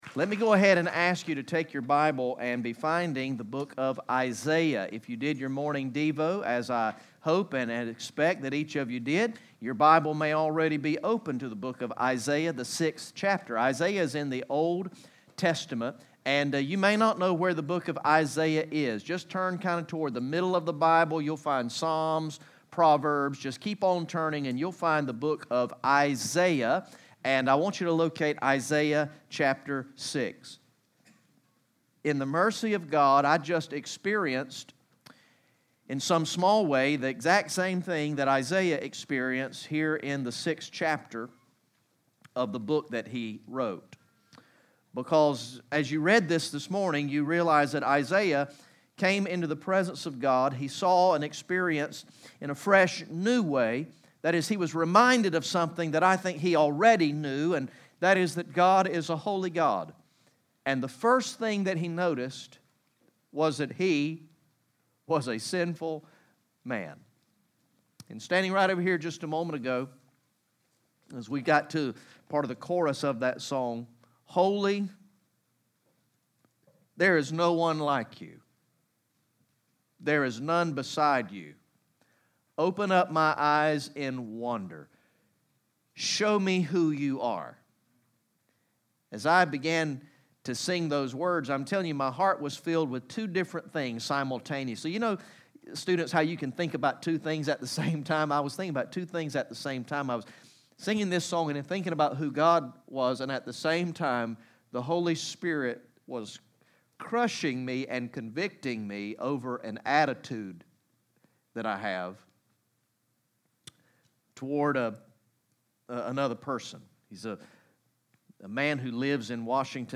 GA From the morning session of ESM Summer Camp on Monday, June 24, 2019